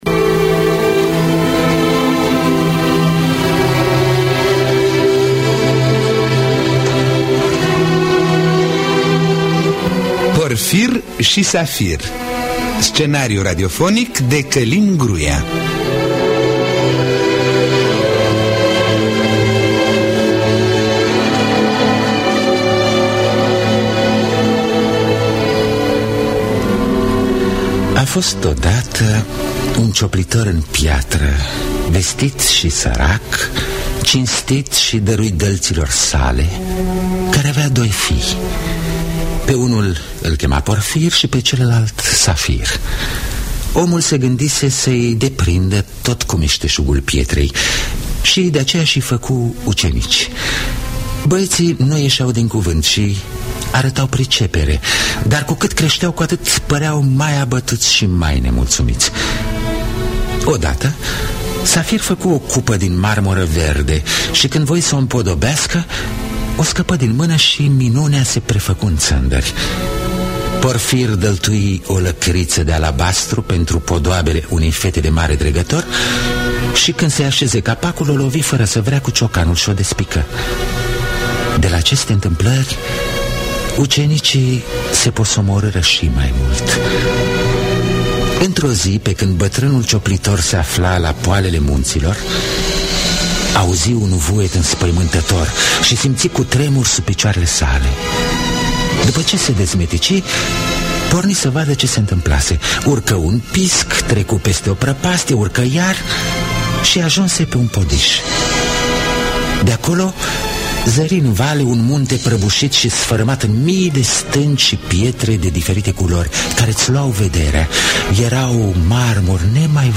Porfir si Safir - basm popular.mp3